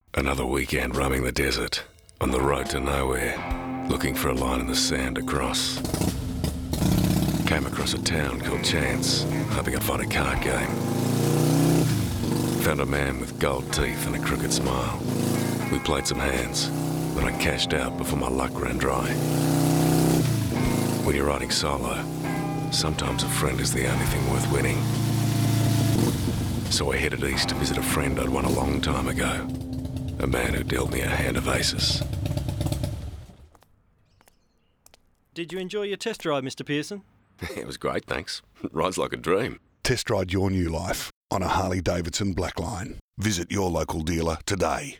In a radio campaign for Harley-Davidson, the motorbike brand plays on the sense of freedom and imagination that its newly launched Blackline gives a man with an invitation to “test drive your new life”.